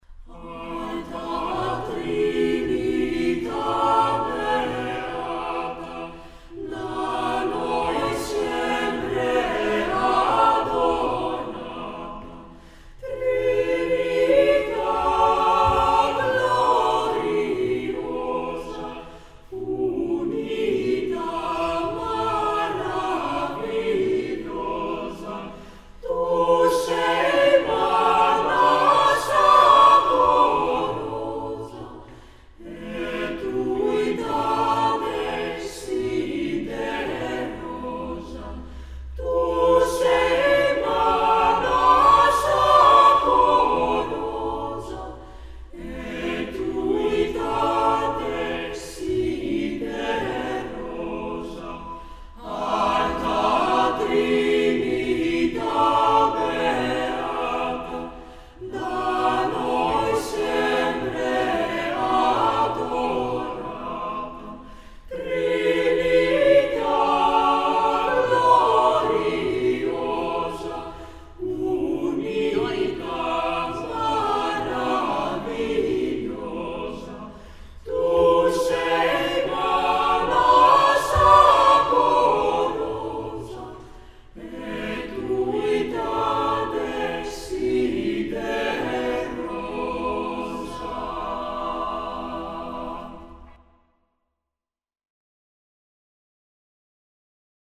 Ηχογράφηση από την πρόβα της Τρίτης 2 Μαΐου 2006.
Με ισοστάθμιση, αντήχηση και στερεοφωνία
eq = Equalizer, rev = reverb